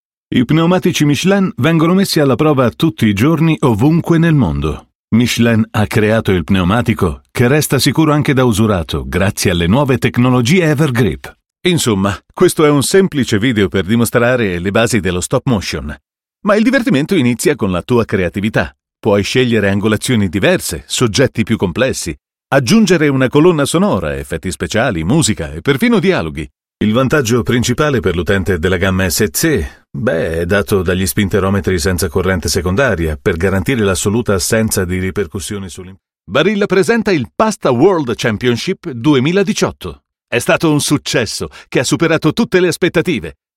Today I produce from my perfectly equipped studio and record my voice for my clients, numerous over the years.
Sprechprobe: Industrie (Muttersprache):